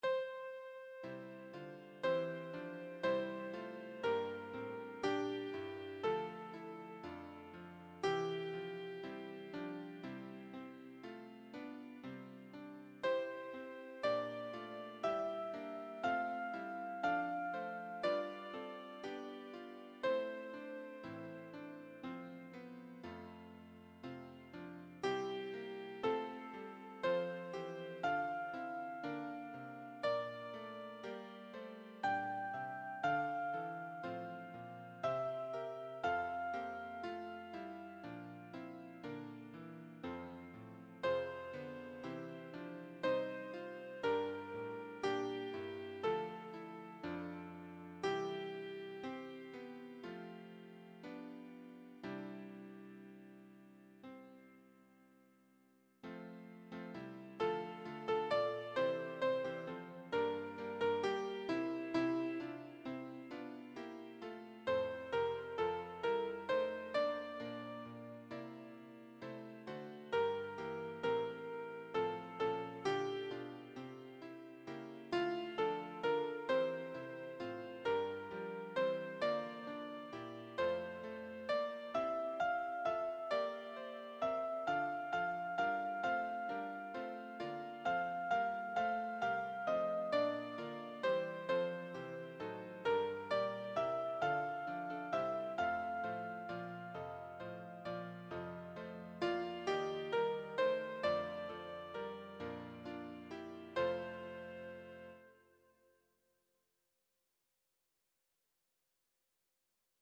Brahms 0p. 74 Warum 3. Langsam und sanft